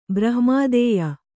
♪ brahma'dēya